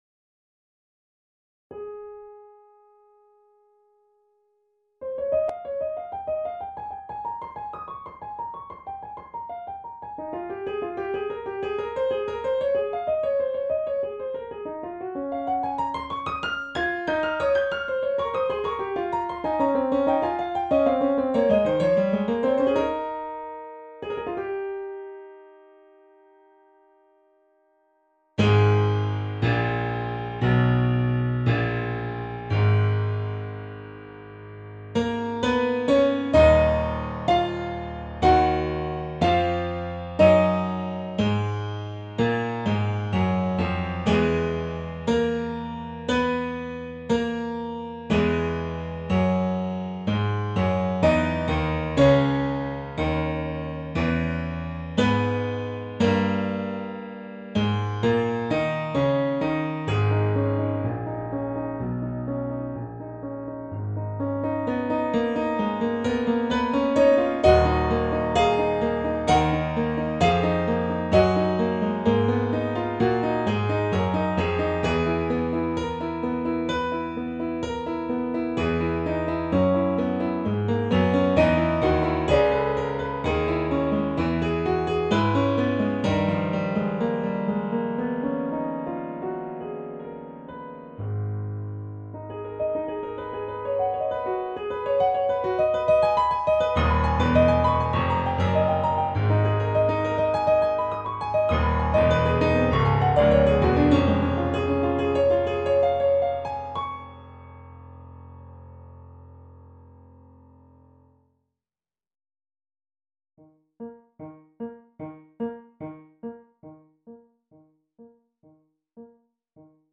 ピアノ楽譜付き